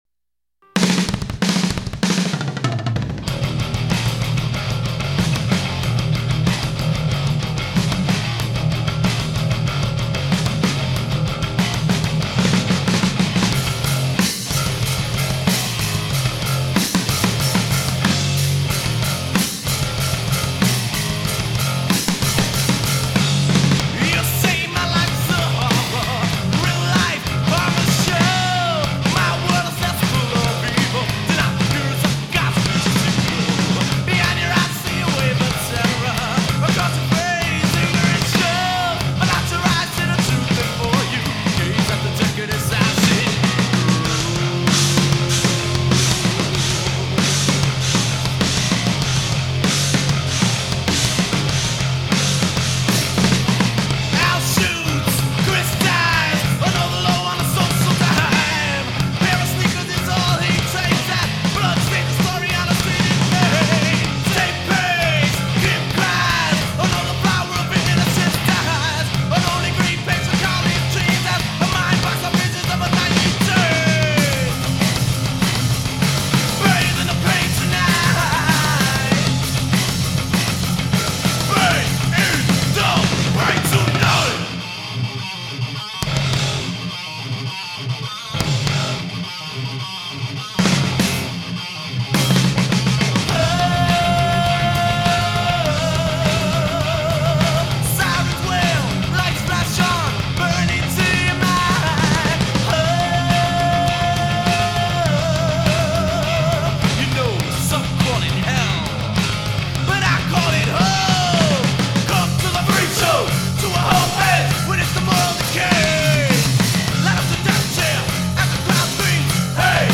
Studio recordings
Bass
Music Unlimited Studios, Bronx, NY